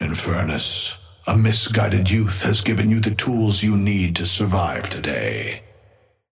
Amber Hand voice line - Infernus, a misguided youth has given you the tools you need to survive today.
Patron_male_ally_inferno_start_03.mp3